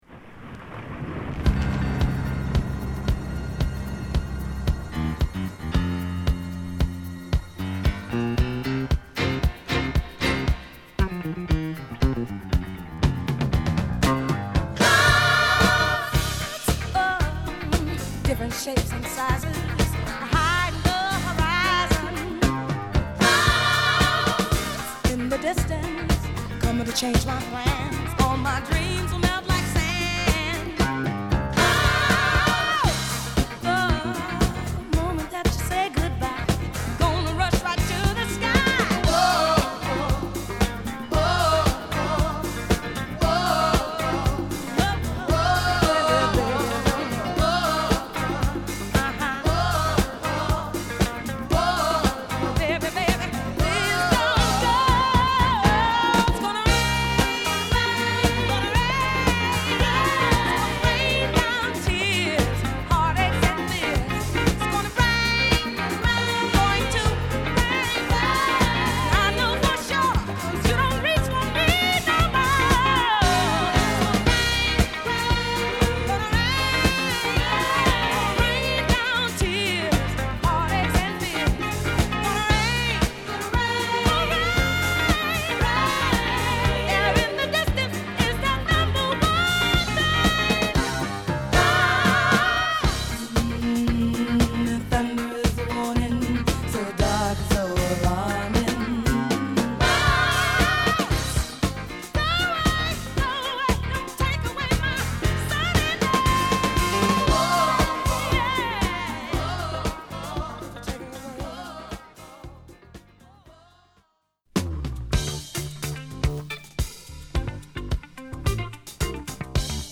流石のこみ上げメロディで盛り上げるガラージ／ダンスクラシックス！